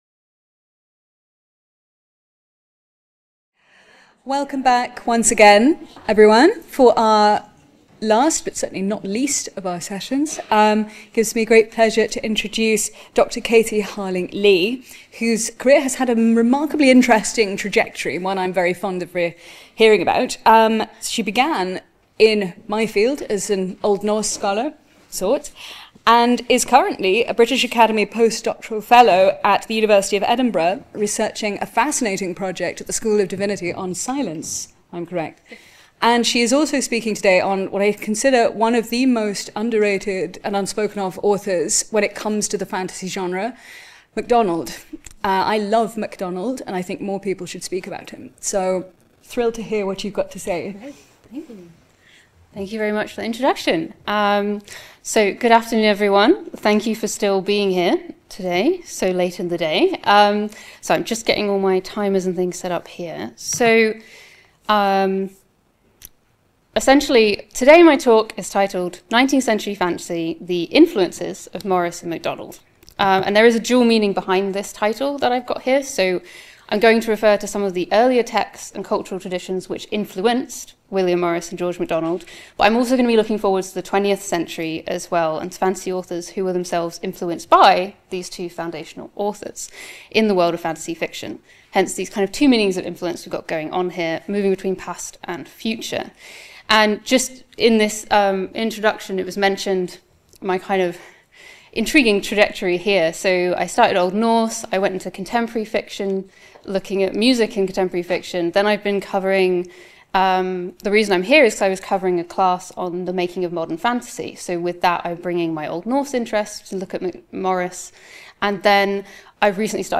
Part of the Bloomsbury-Oxford Summer School (23rd-25th September 2025) held at Exeter College.